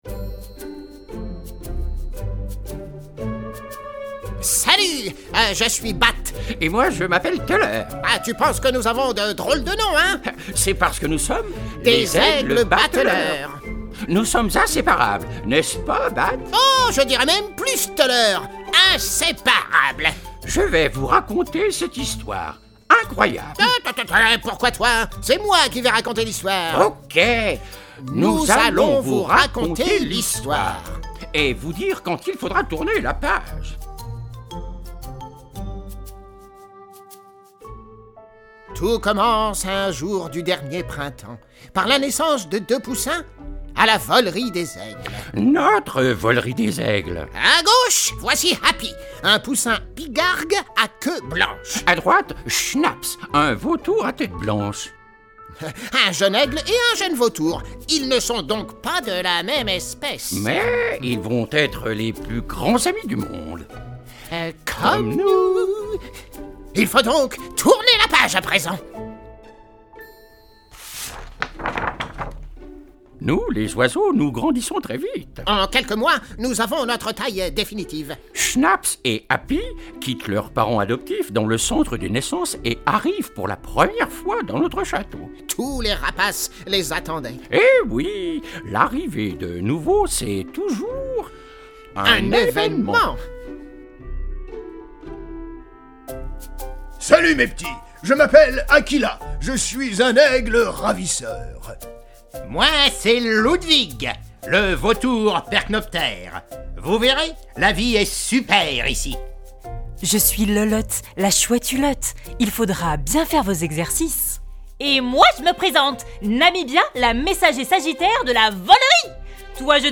L'envol de Hapy | Livre audio pour enfants
L'envol de Hapy, un livre audio pour les enfants de 3 à 7 ans